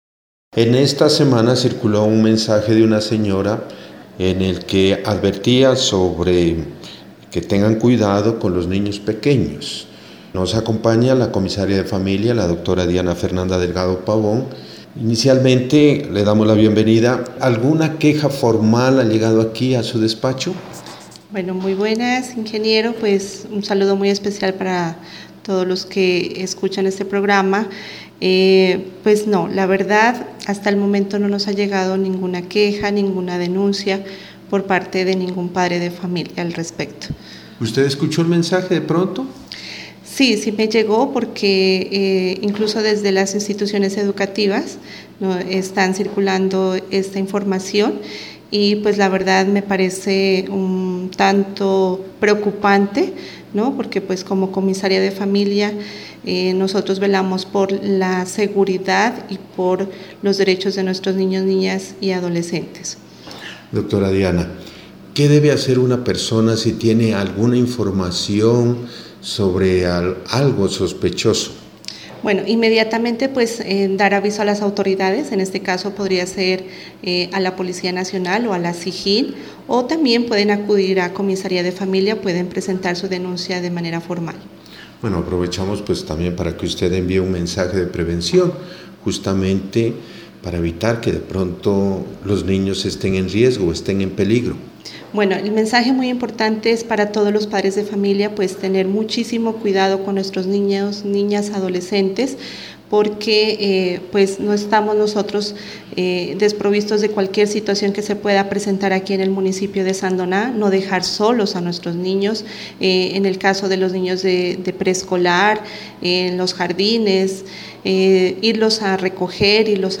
Entrevista con la comisaria de familia Diana Fernanda Delgado Pabón: